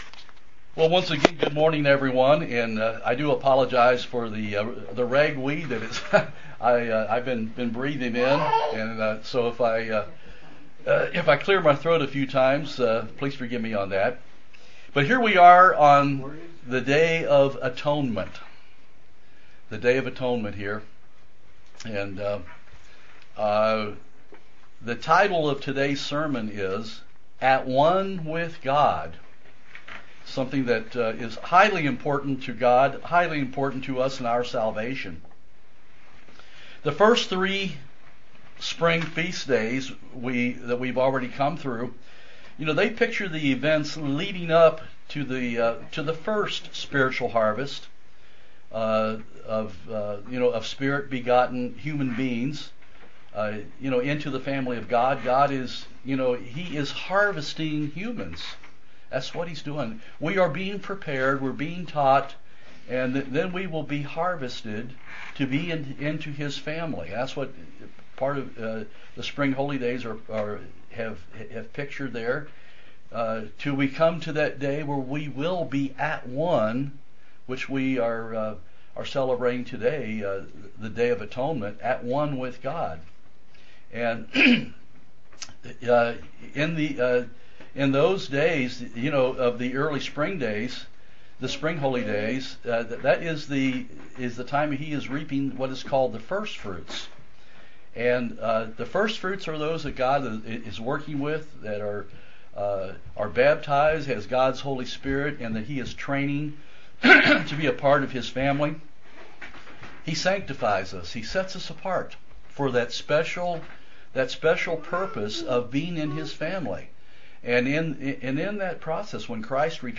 Print This shows how we are becoming one with God UCG Sermon Studying the bible?